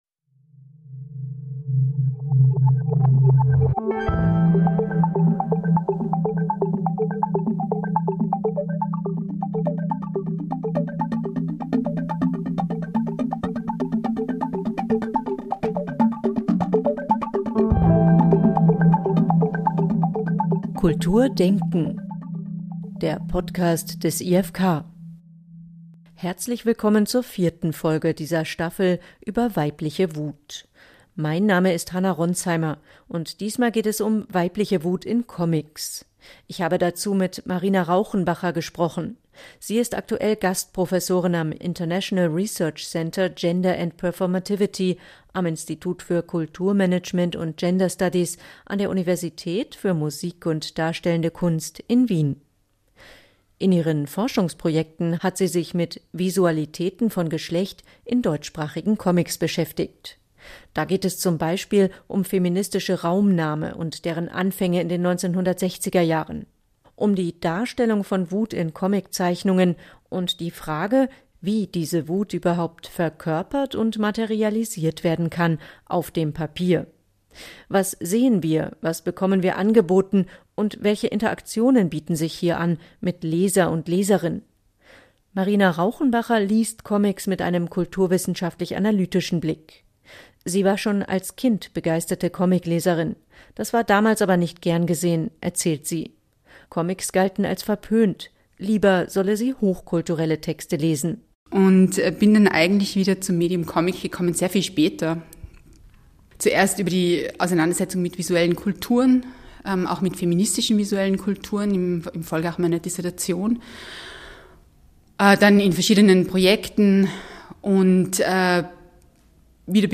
Season 6 Episode 4: All the Rage. Gespräch